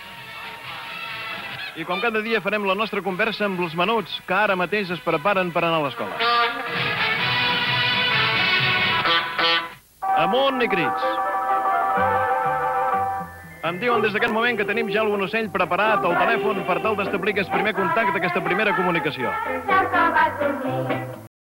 Infantil-juvenil
FM